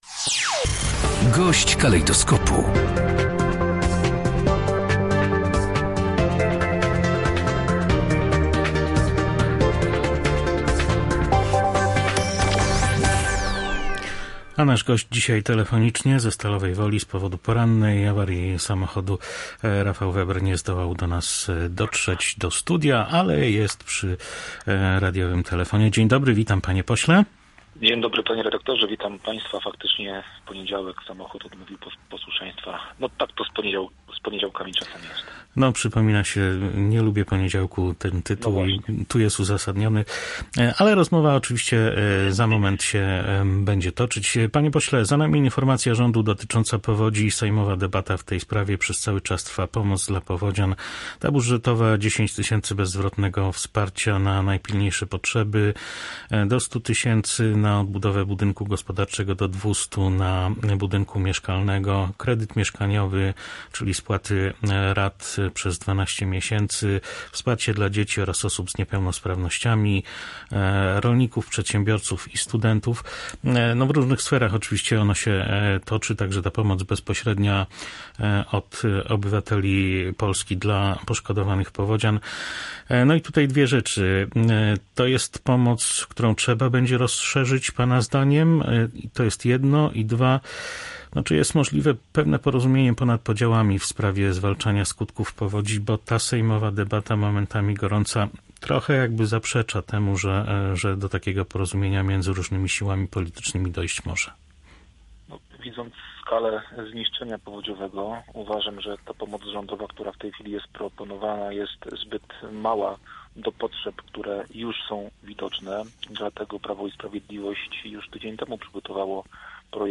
-Tak duże zniszczenia to skutek braku działań rządu – twierdzi gość Polskiego Radia Rzeszów, poseł PiS Rafał Weber.